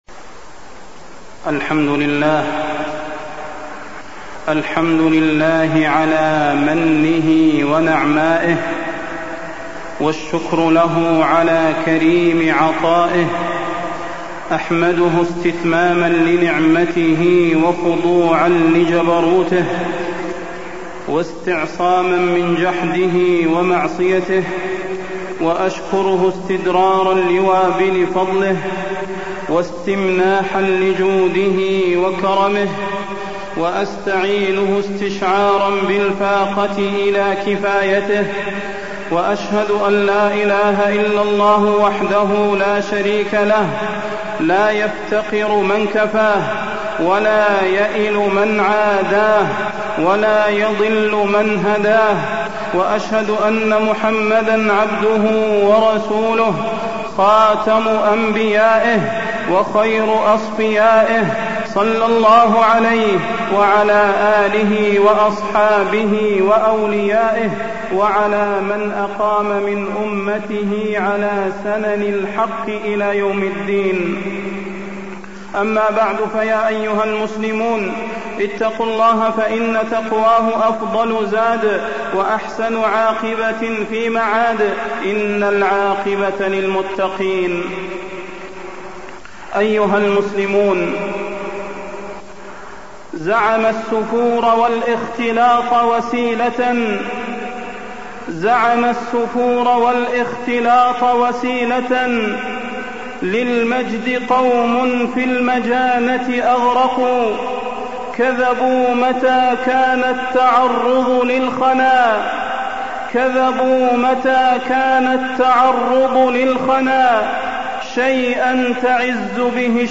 فضيلة الشيخ د. صلاح بن محمد البدير
تاريخ النشر ٢٨ جمادى الأولى ١٤٢٥ هـ المكان: المسجد النبوي الشيخ: فضيلة الشيخ د. صلاح بن محمد البدير فضيلة الشيخ د. صلاح بن محمد البدير السفور والإختلاط